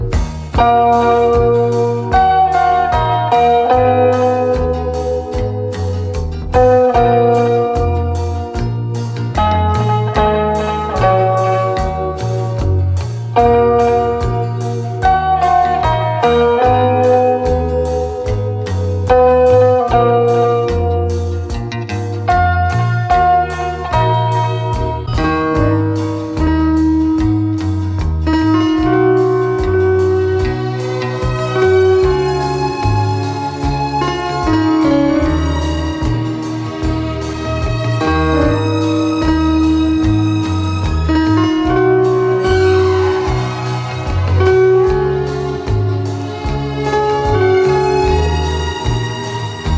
• Качество: 187, Stereo
лирика
пианино
OST